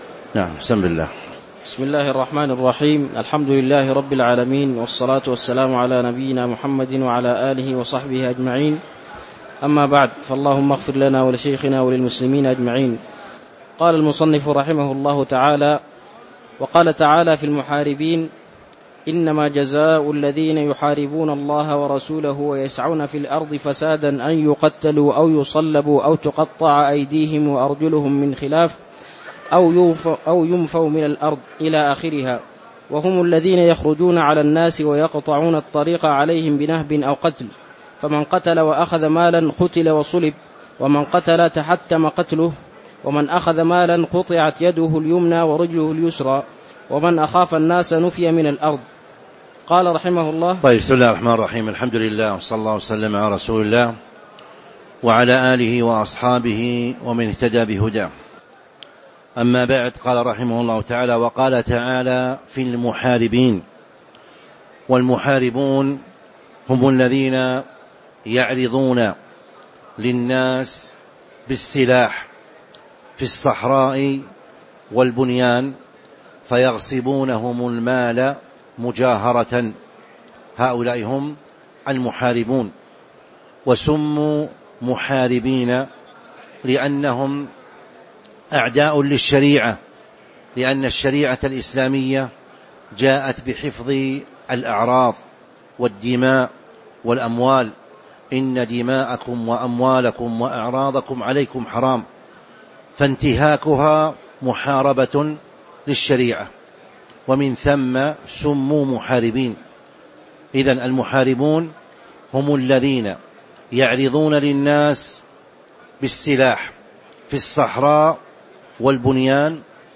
تاريخ النشر ٢٢ رمضان ١٤٤٦ هـ المكان: المسجد النبوي الشيخ